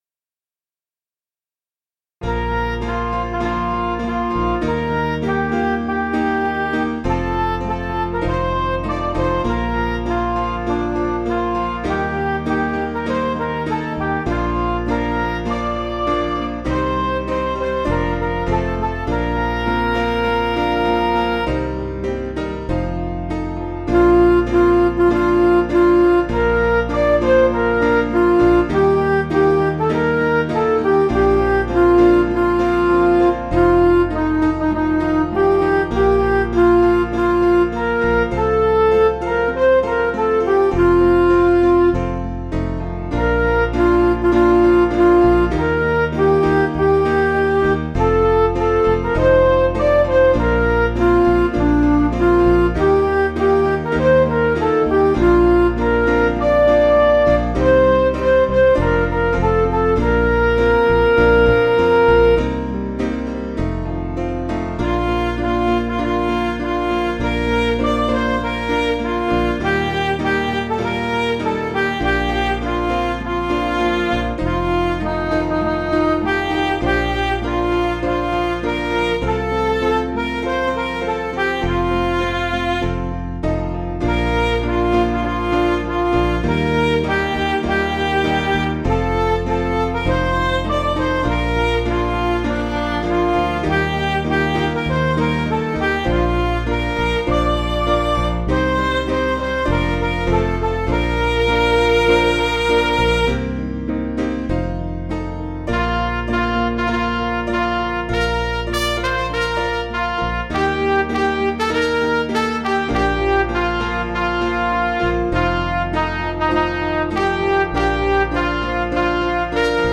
Piano & Instrumental
(CM)   3/Bb